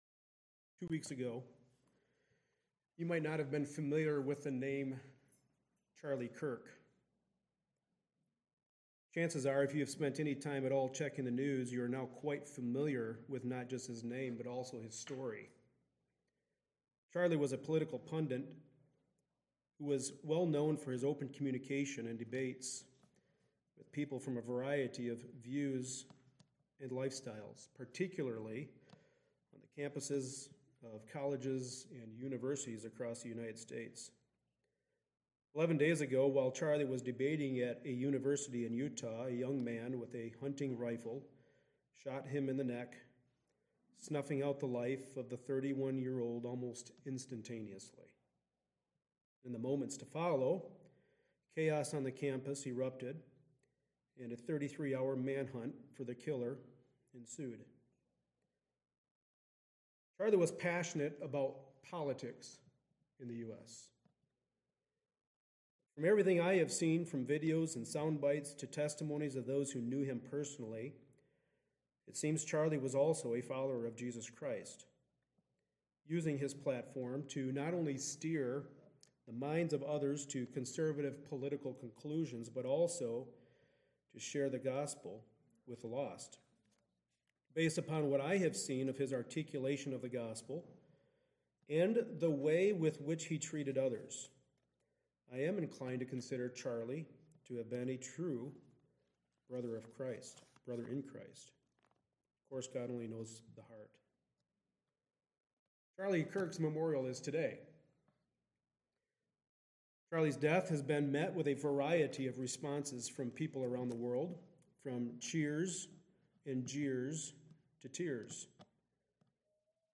Passage: Matthew 5:38-48 Service Type: Sunday Morning Service Related Topics